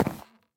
wood2.mp3